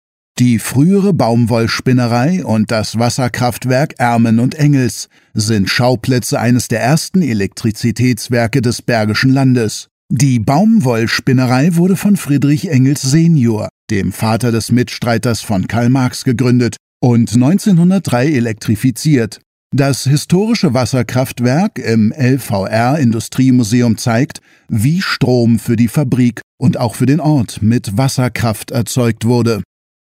audioguide-industriemuseum.mp3